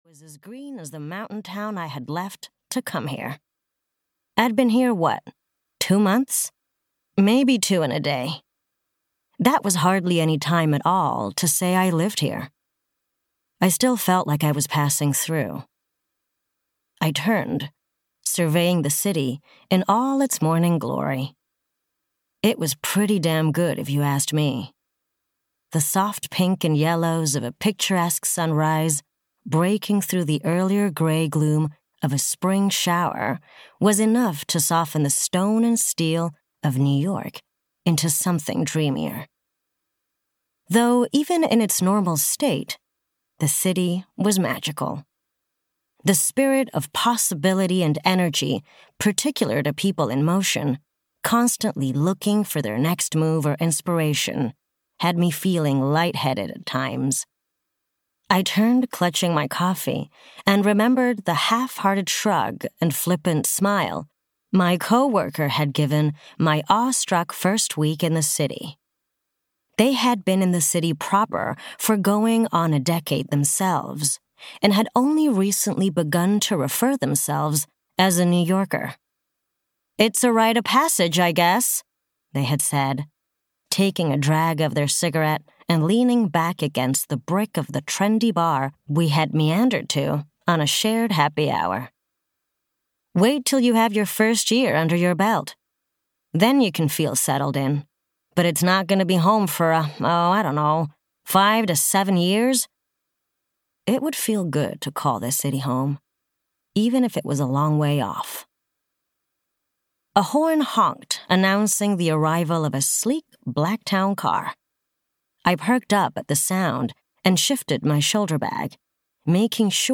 New Girl In The City (EN) audiokniha
Ukázka z knihy
new-girl-in-the-city-en-audiokniha